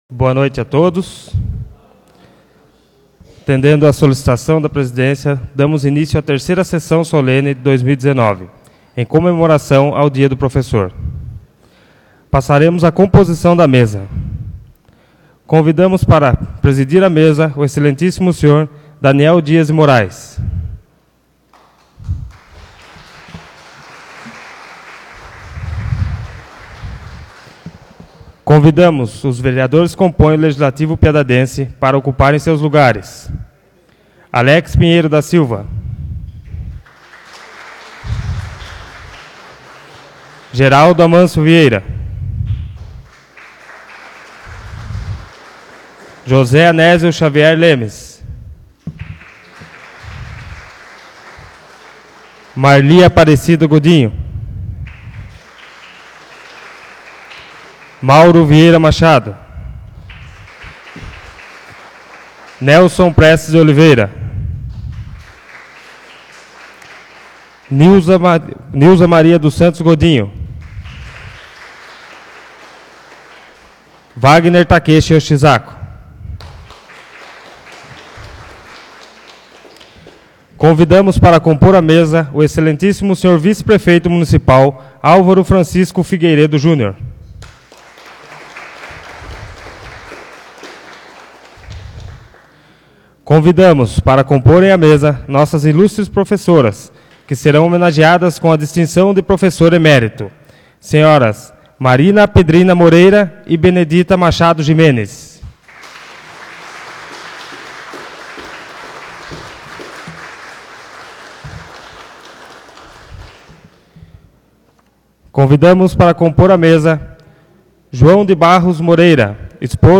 3ª Sessão Solene de 2019